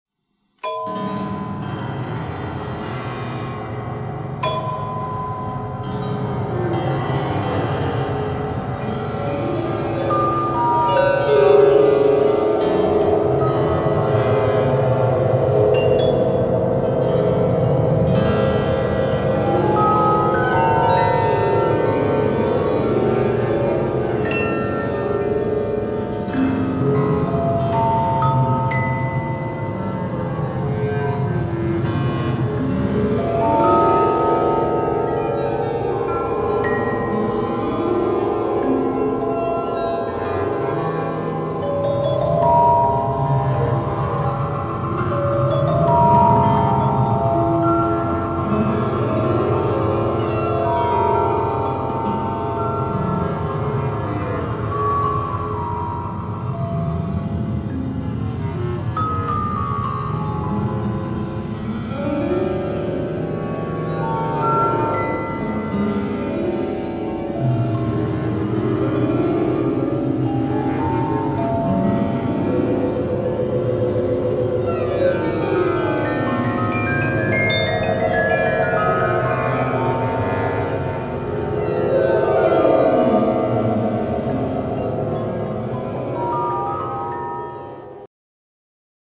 magnificent and epic score